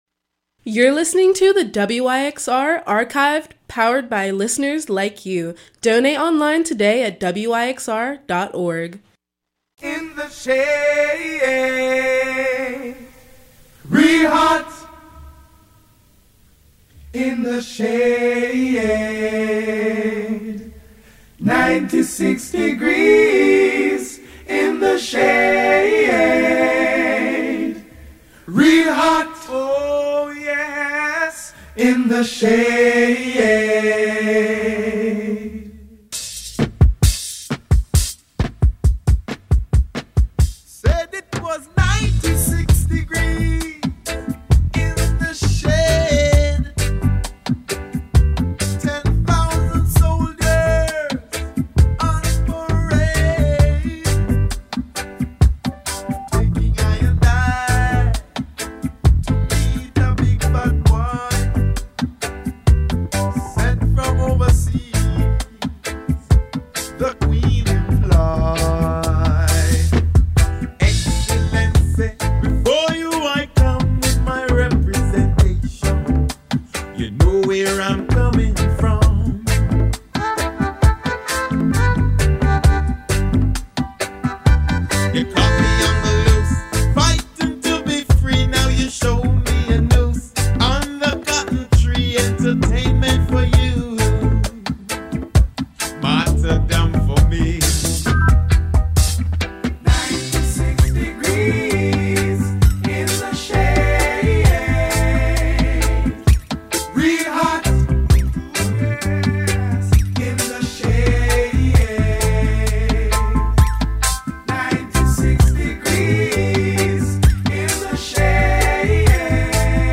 World Jazz Soul